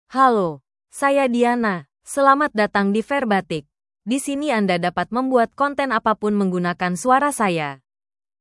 Diana — Female Indonesian (Indonesia) AI Voice | TTS, Voice Cloning & Video | Verbatik AI
DianaFemale Indonesian AI voice
Voice sample
Listen to Diana's female Indonesian voice.
Female
Diana delivers clear pronunciation with authentic Indonesia Indonesian intonation, making your content sound professionally produced.